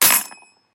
coin.ogg